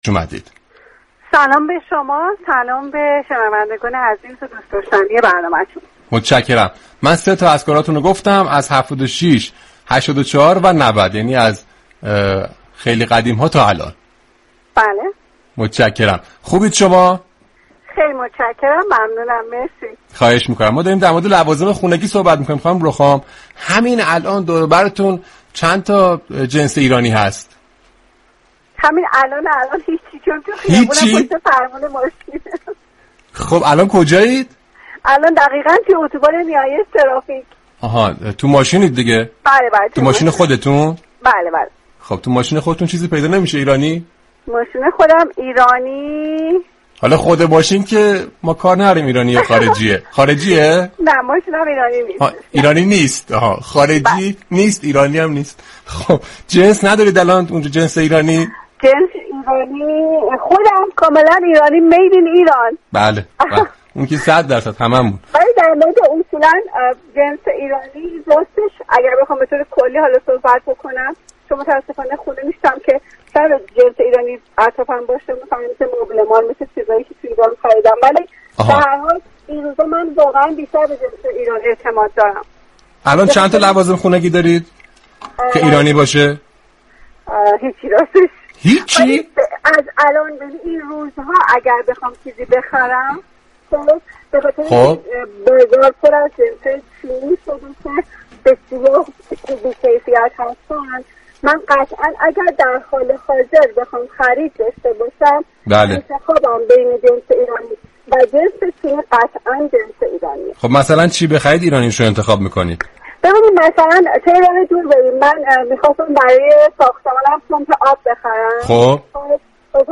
رادیو صبا در «برنامه عصر صبا» با شراره رخام درباره حمایت از كالای ایرانی گفتگو صمیمی داشت.